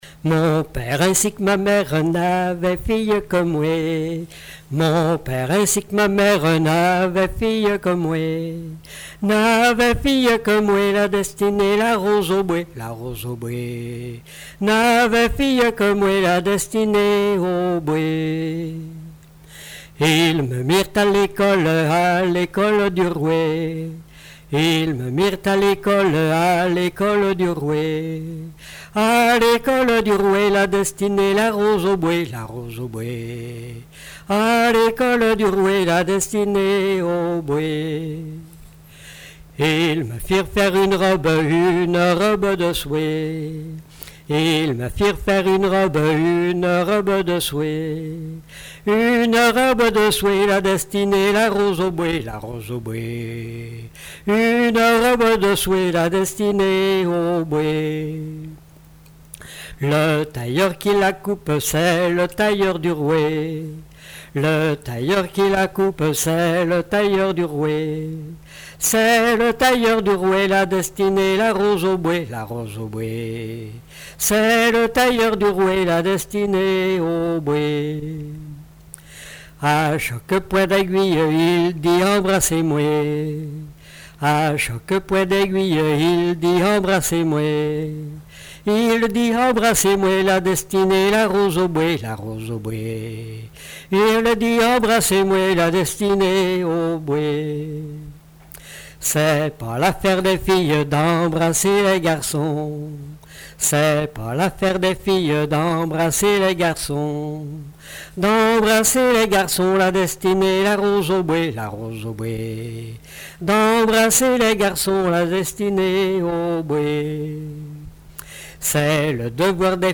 Genre laisse
Témoignages et chansons
Pièce musicale inédite